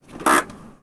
Freno de mano de un coche
freno de mano
Sonidos: Transportes